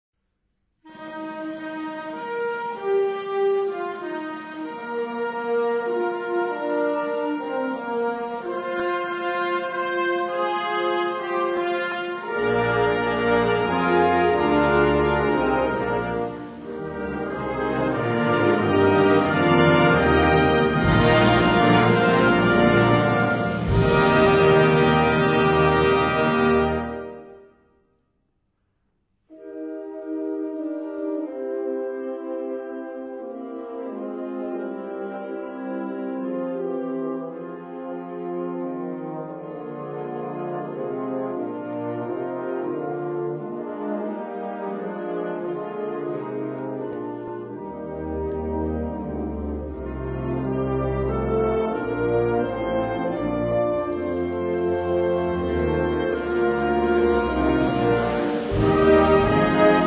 Gattung: Feierliche Musik
Besetzung: Blasorchester
Diese religiös angehauchte Komposition